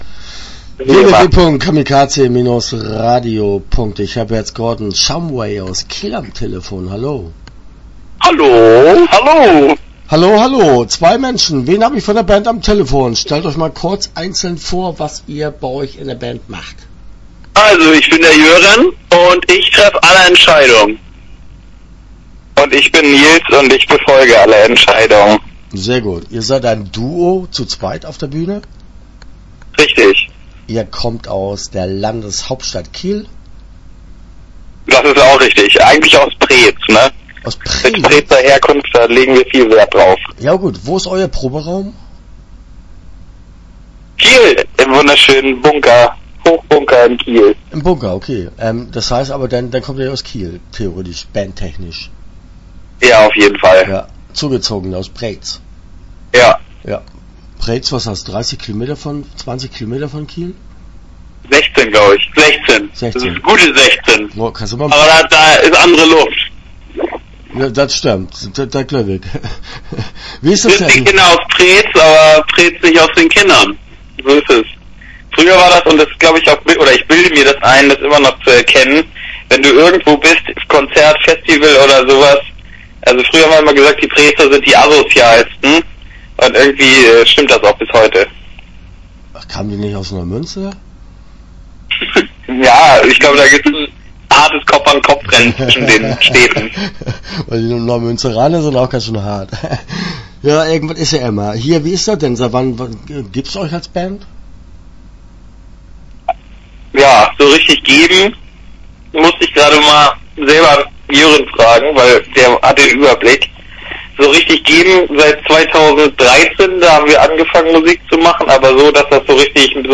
Start » Interviews » Gordon Shumway